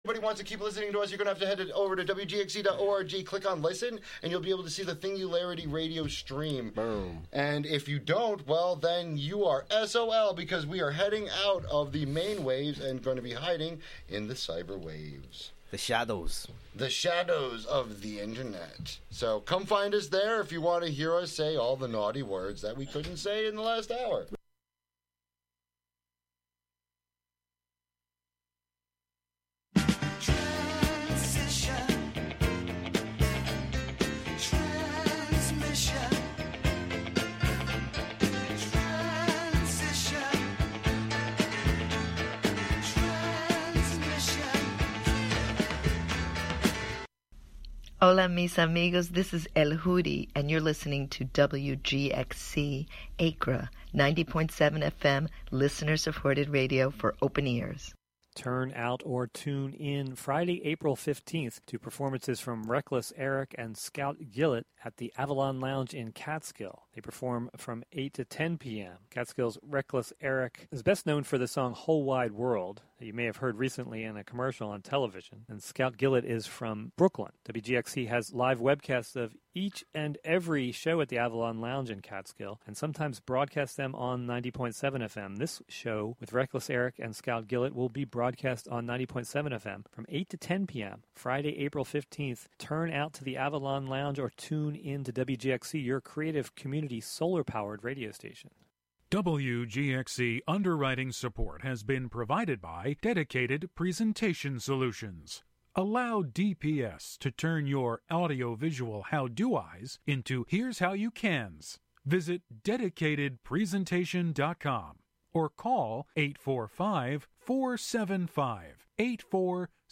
The Love Motel is a monthly late-night radio romance talk show with love songs, relationship advice, and personals for all the lovers in the upper Hudson Valley.